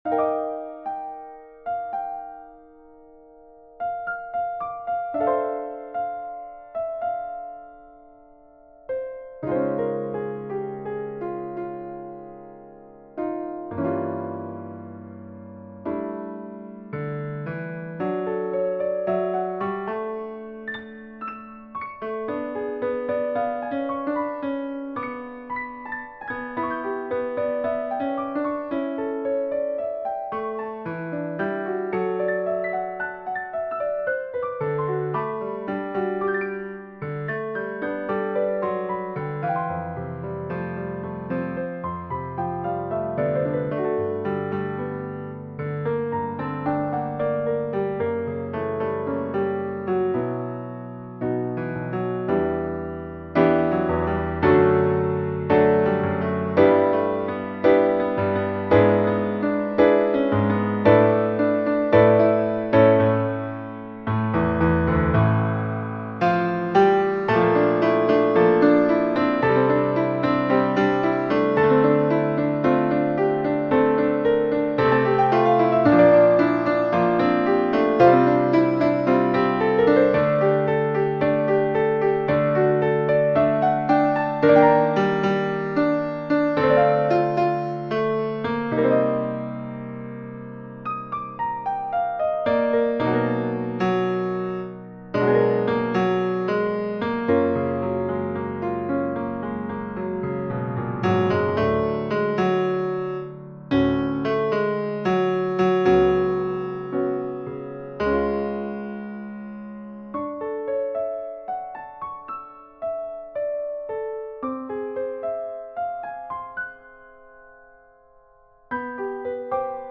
Solo voice and piano